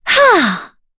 Yuria-Vox_Casting1_kr.wav